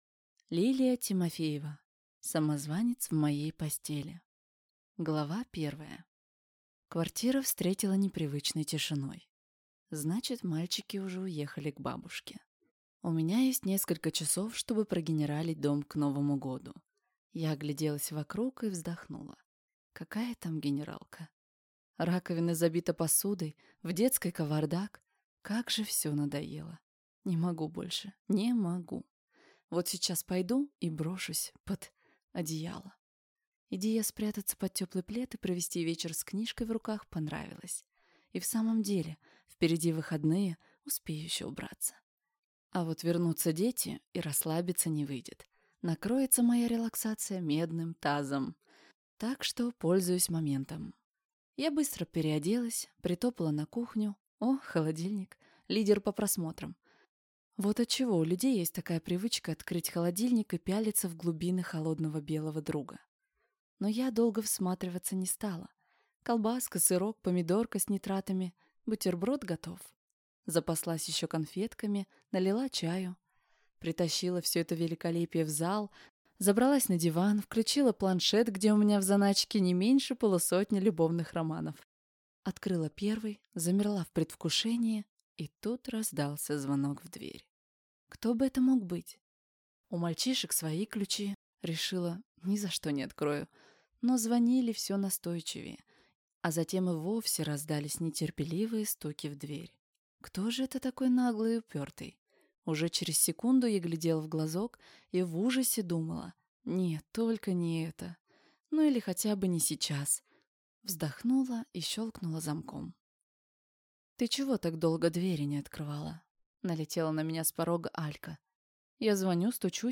Аудиокнига Самозванец в моей постели | Библиотека аудиокниг